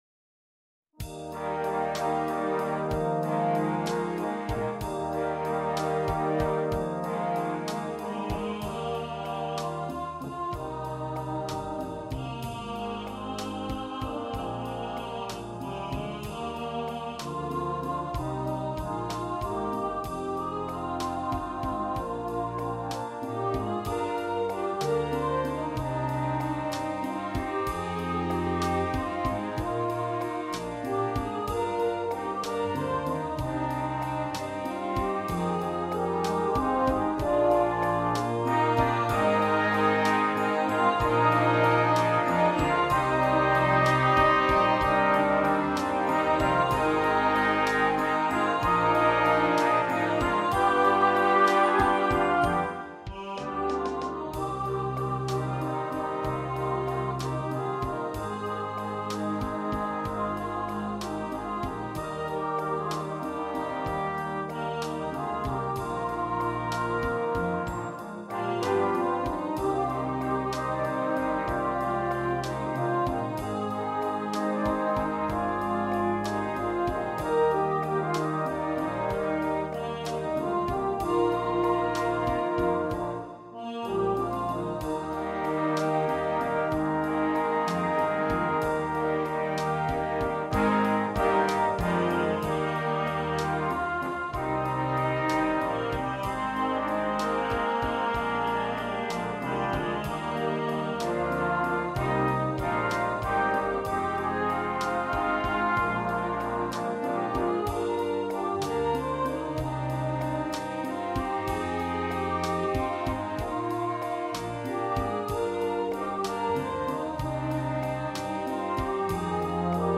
Besetzung: Vocal Solo & Brass Band